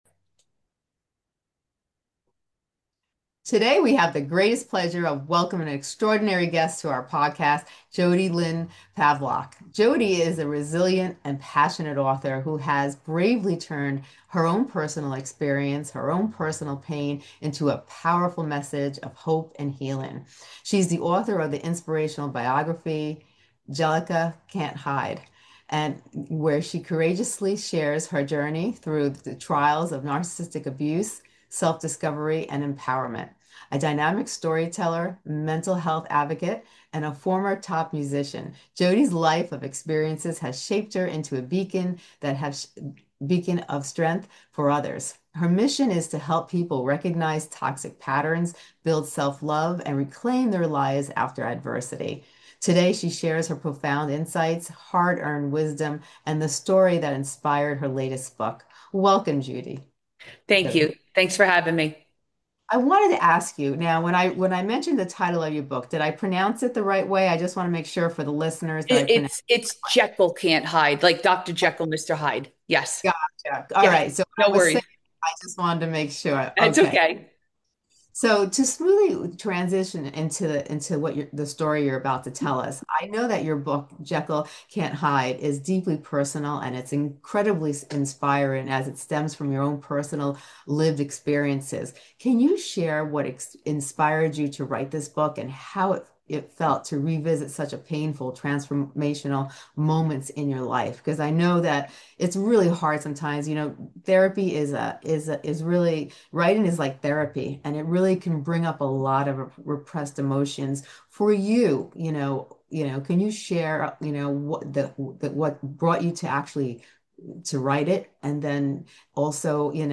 👉 Love insightful and life-changing interviews?